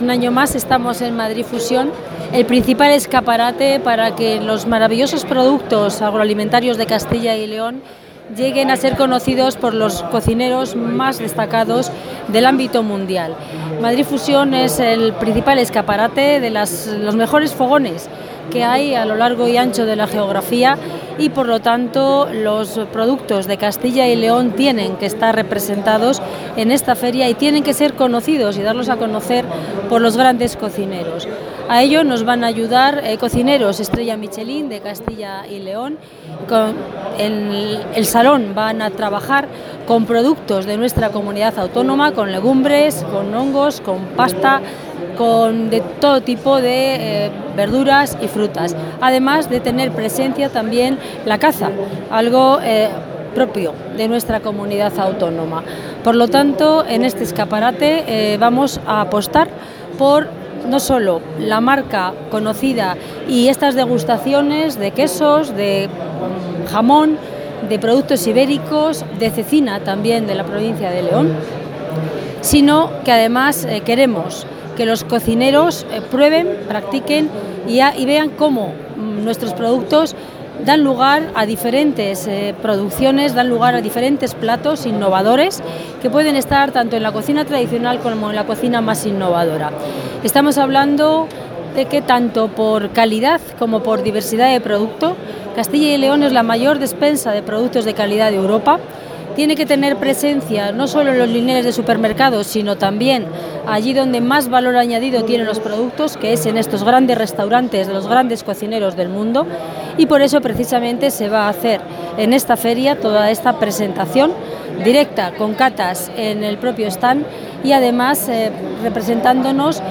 Material audiovisual de la asistencia de la consejera de Agricultura y Ganadería a Madrid Fusion
Declaraciones de la consejera de Agricultura y Ganadería.